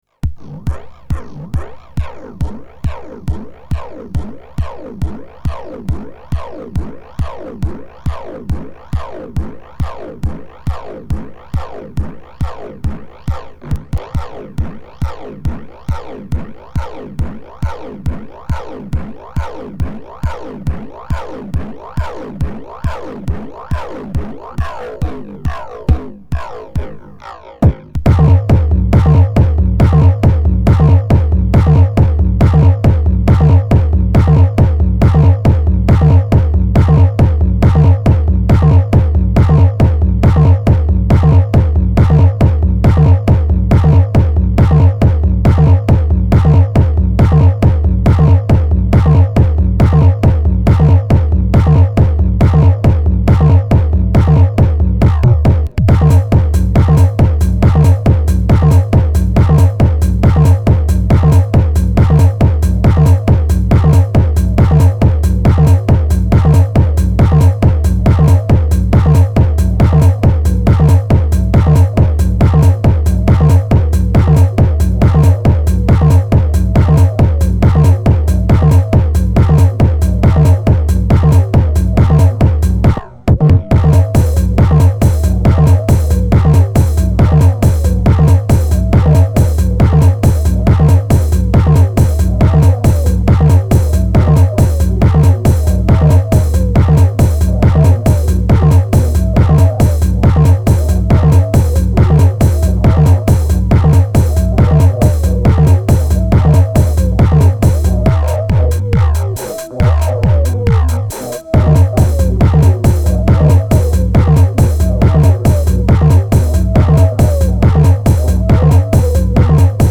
Minimal techno track made out of a JoMoX X-Base 09 preset.
138 BPM